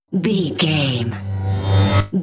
Riser sci fi electronic flashback
Sound Effects
Atonal
bouncy
futuristic
intense
tension
riser